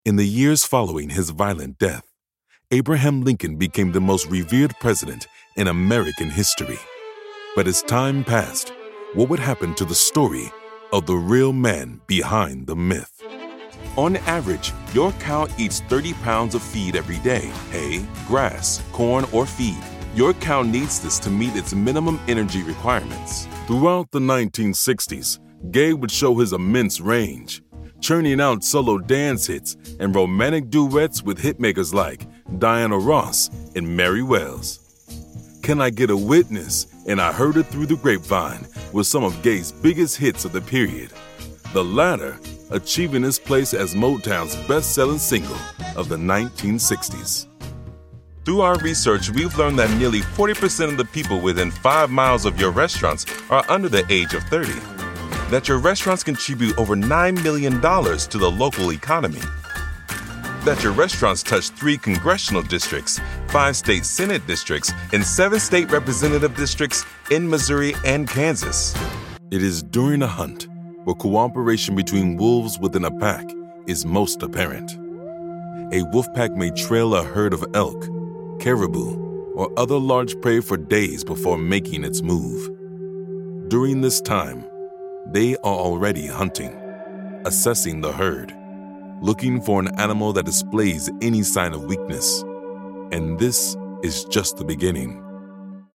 Deep Voice, Powerful, Epic
Narration